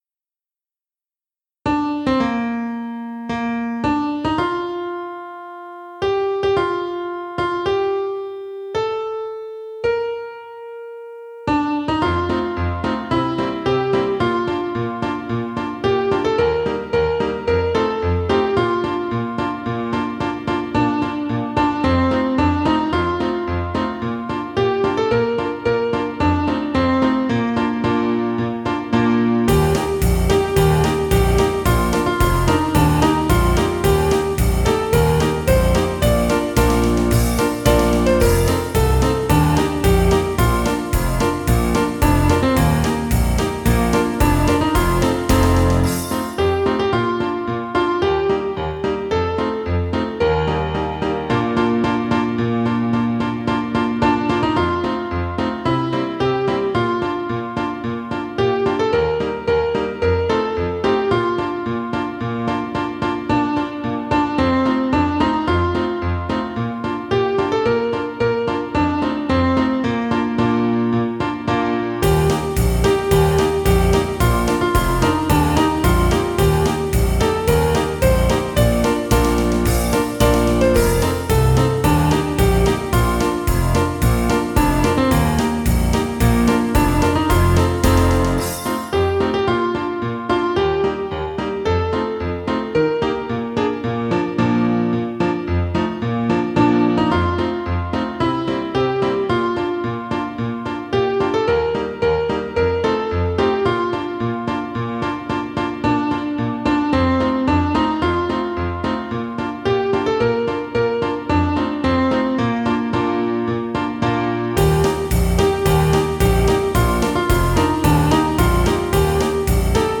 校歌(松島商業高校）/伴奏    1954年（昭和29年） - 熊本県立商業高等学校別科今津分室創設
matusimasho_kouka_banso.mp3